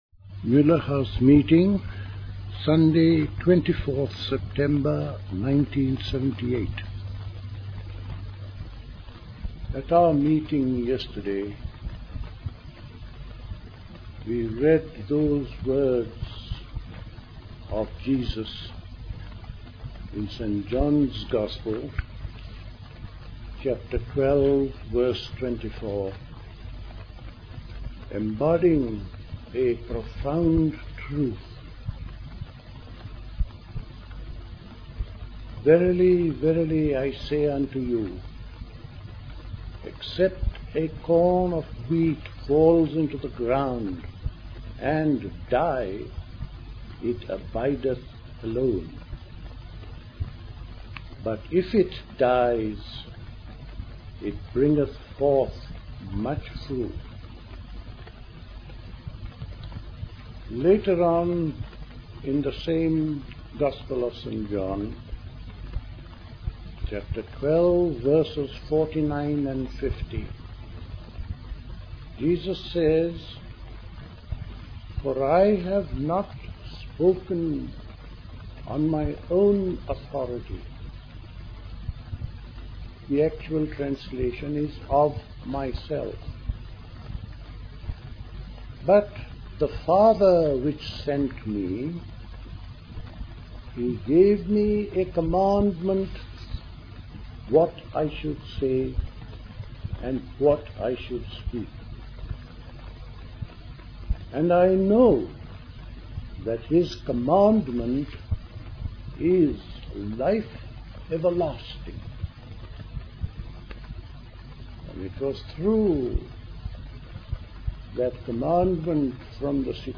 A talk
at Elmau, Bavaria